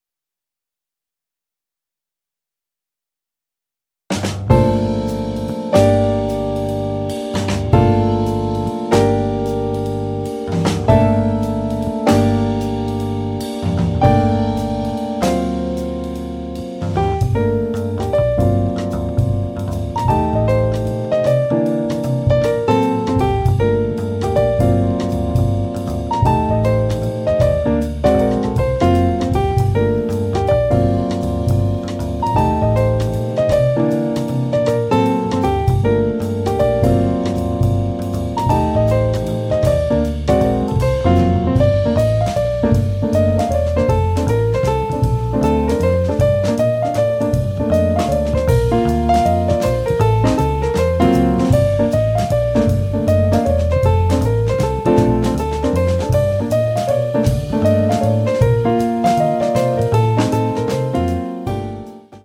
piano
contrabbasso e basso elettrico
batteria